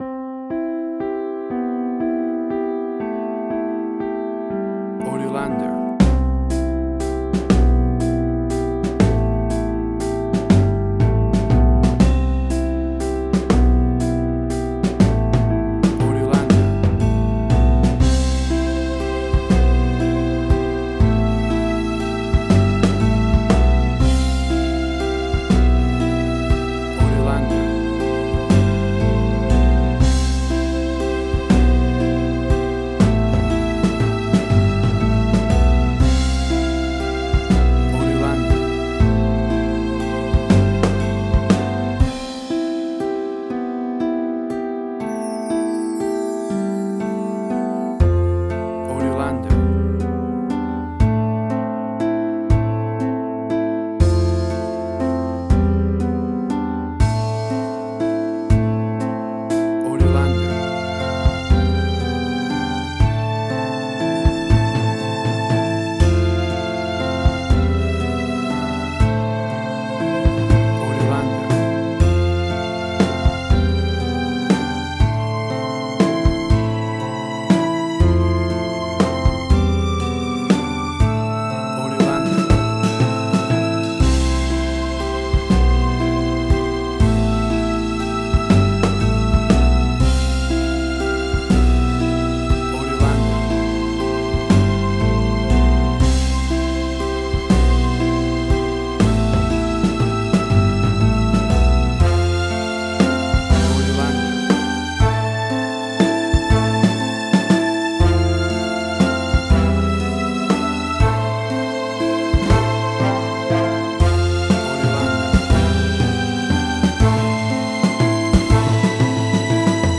Indie Quirky.
Tempo (BPM): 119